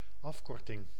Ääntäminen
Synonyymit abréviature Ääntäminen France (Île-de-France): IPA: [yn a.bʁe.vja.sjɔ̃] Tuntematon aksentti: IPA: /a.bʁe.vja.sjɔ̃/ Haettu sana löytyi näillä lähdekielillä: ranska Käännös Ääninäyte Substantiivit 1. afkorting {f} 2. bekorting Suku: f .